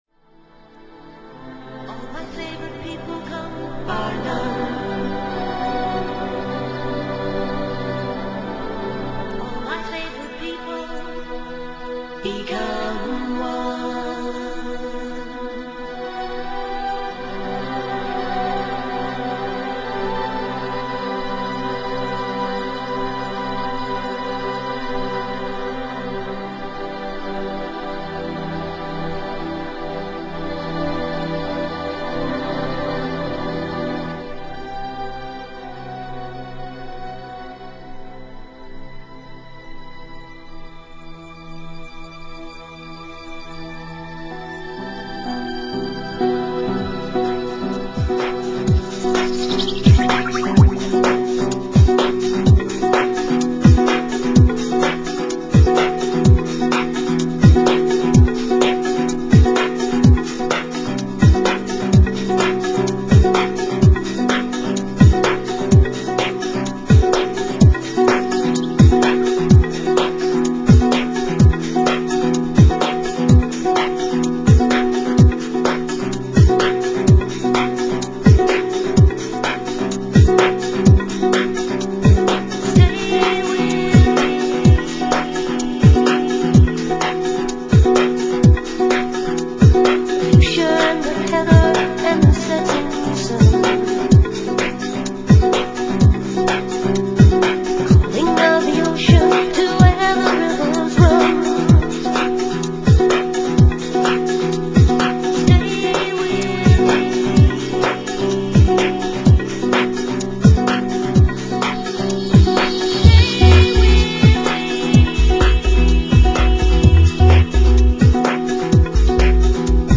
Chill Out系の傑作!!